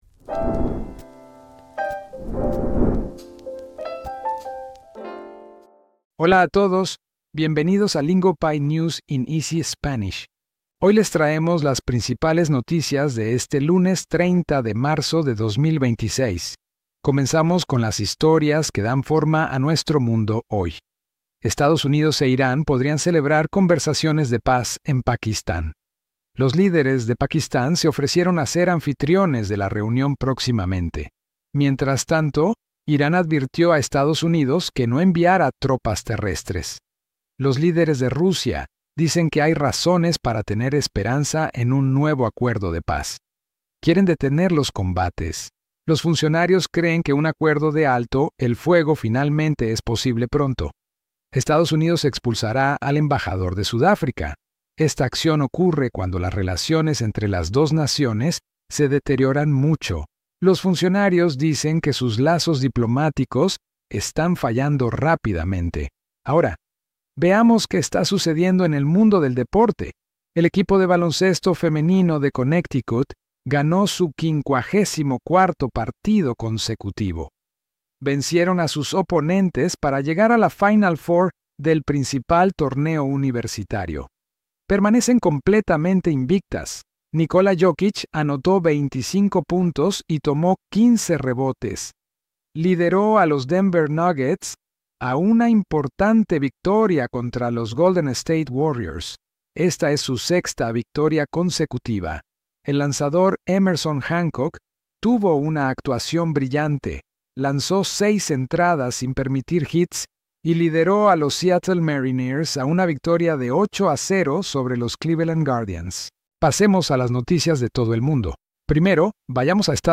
Today’s biggest stories, slowed down into clear, beginner-friendly Spanish so your listening stays easy.